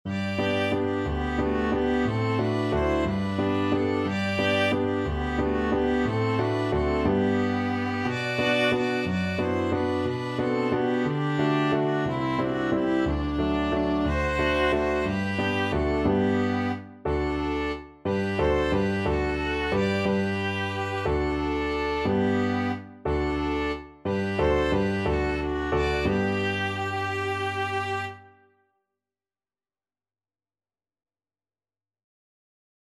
Violin-Viola Duet version
(with piano)
ViolinViolaPiano
3/4 (View more 3/4 Music)